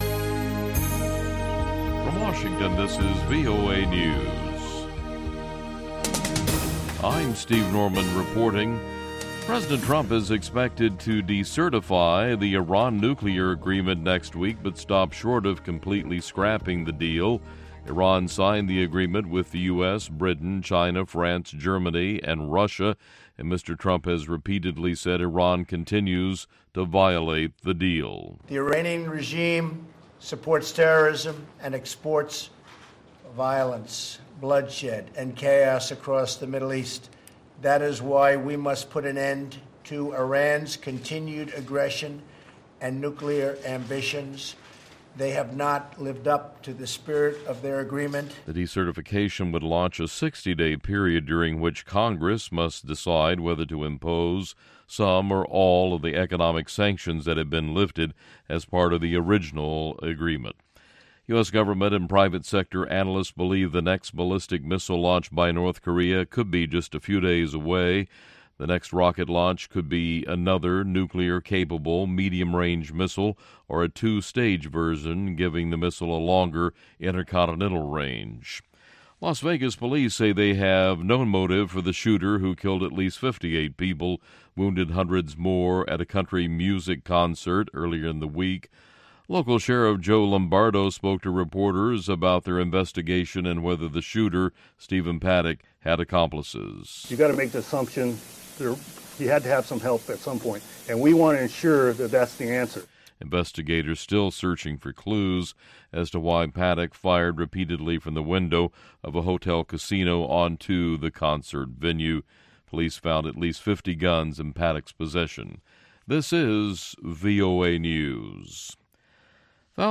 Discover neo-traditional sounds from Abidjan and Brazza! She's got you covered for music news, too: A brother duo launches their new album in Kenya - get the full scoop from the artists themselves in Nairobi.